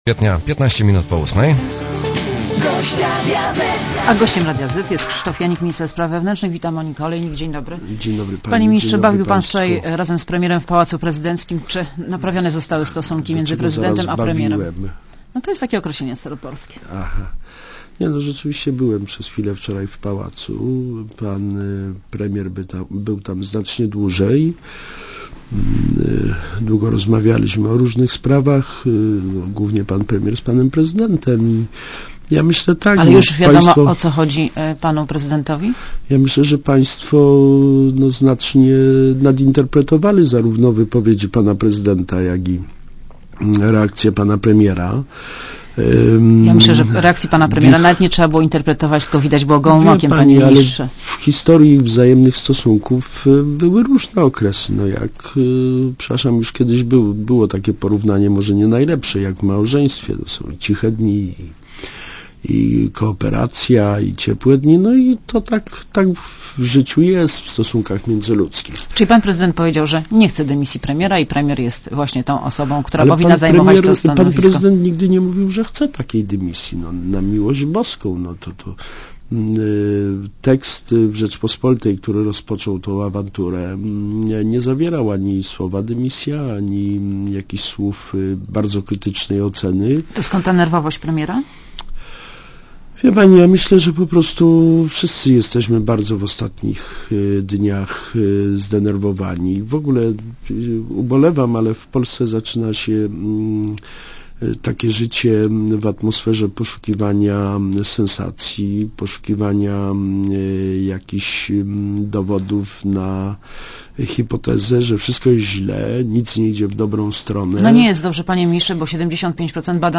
Monika Olejnik rozmawia z Krzysztofem Janikiem -ministrem spraw wewnętrznych
© Krzysztof Janik w Radiu Zet (RadioZet) © (RadioZet) Posłuchaj wywiadu (2,6 MB) Panie Ministrze, bawił pan wczoraj razem z premierem w pałacu prezydenckim - czy naprawione zostały stosunki między prezydentem a premierem?